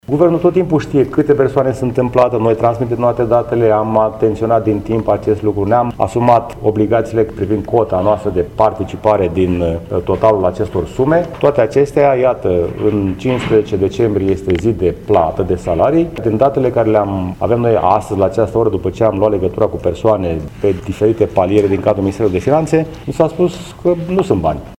Primarul George Scripcaru: